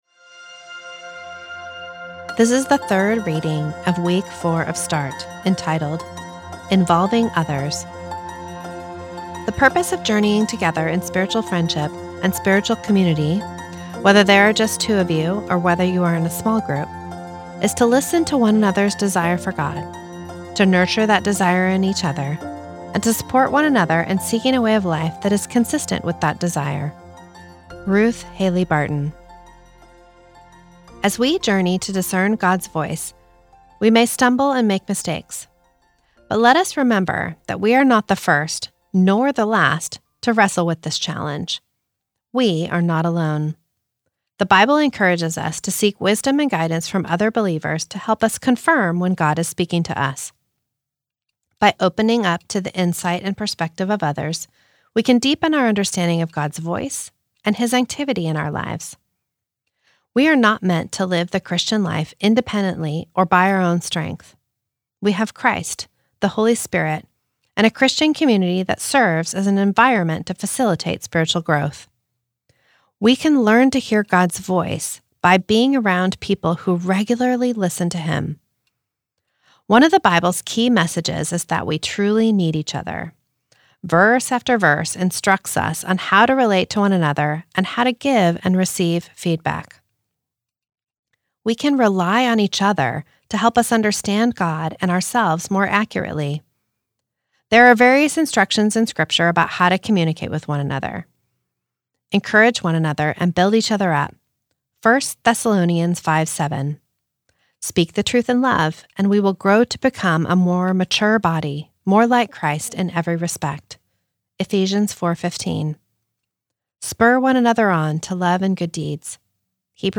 This is the audio recording of the third reading of week four of Start, entitled Involving Others.